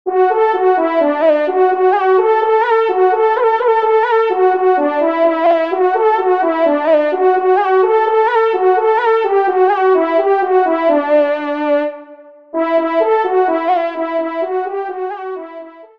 Solo Trompe      (Ton de vénerie)